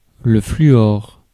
Ääntäminen
Synonyymit difluor gaz fluor Ääntäminen France Tuntematon aksentti: IPA: /fly.ɔʁ/ Haettu sana löytyi näillä lähdekielillä: ranska Käännös Konteksti Substantiivit 1. fluor kemia Suku: m .